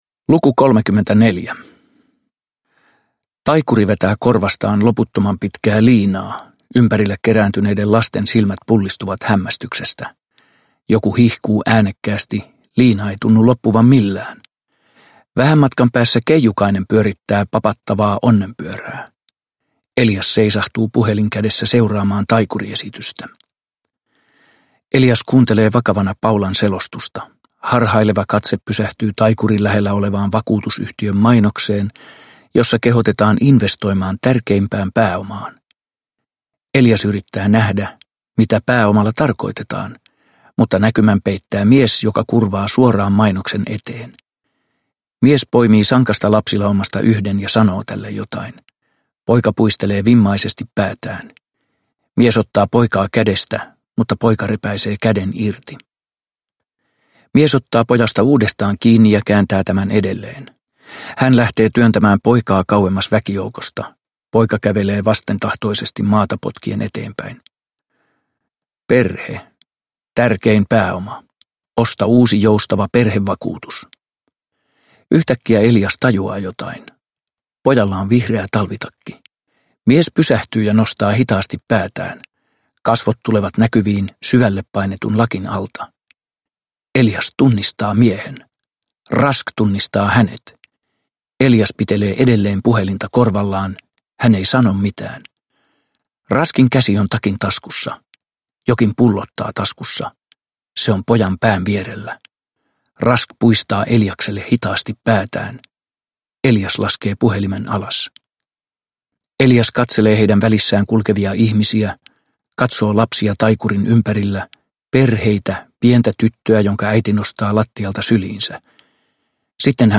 Uppläsare: Pirkka-Pekka Petelius